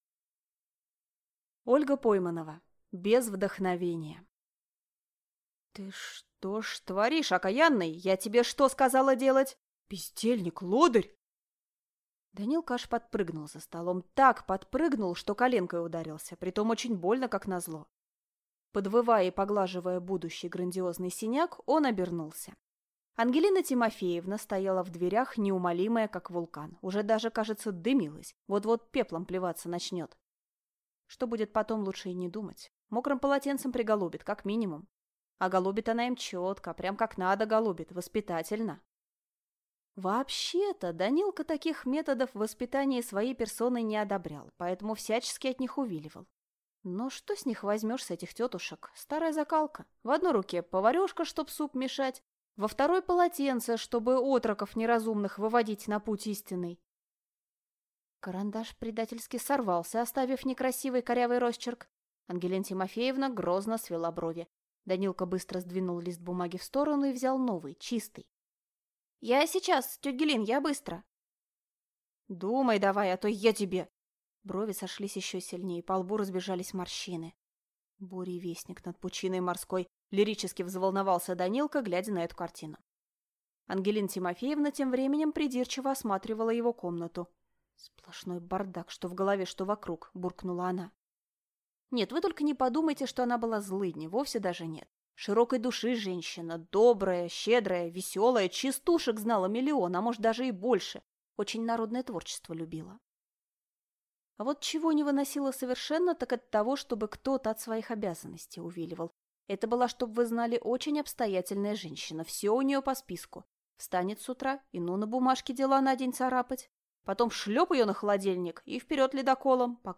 Аудиокнига Без вдохновения | Библиотека аудиокниг